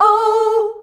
OUUH  A.wav